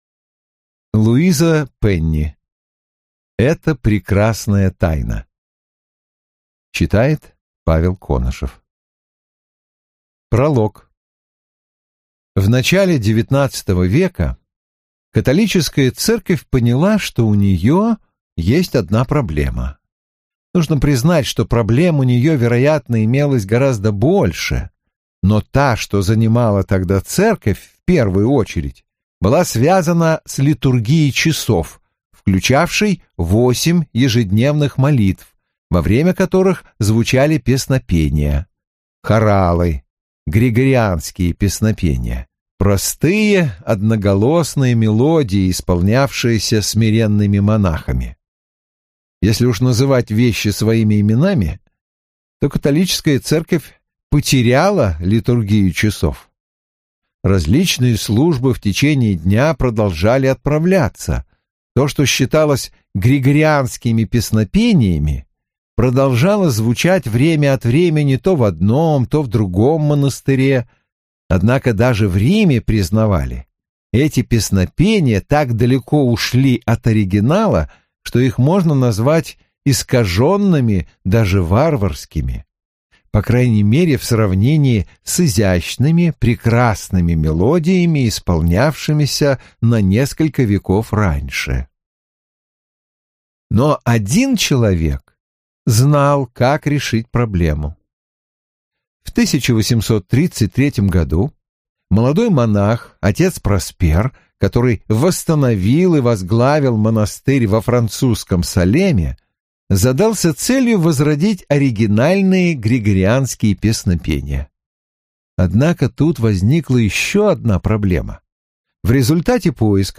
Аудиокнига Эта прекрасная тайна - купить, скачать и слушать онлайн | КнигоПоиск